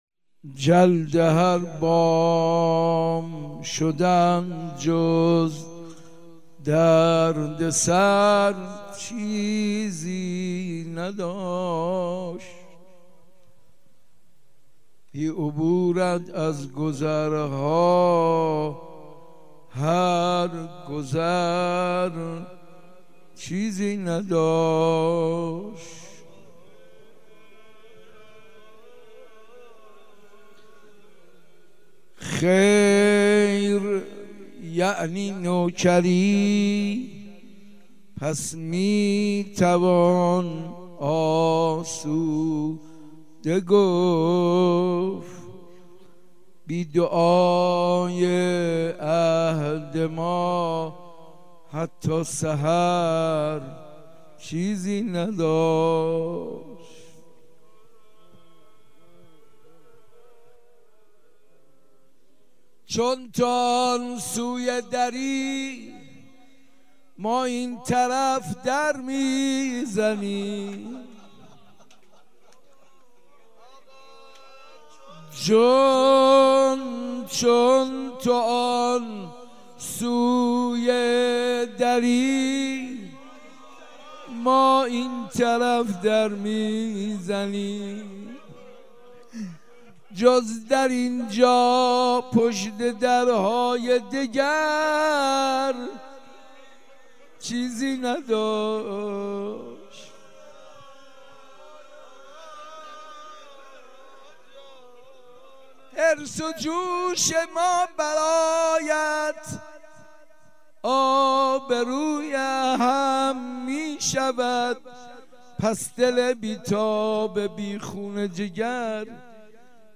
مداحی شنیدنی